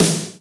drum-hitclap.ogg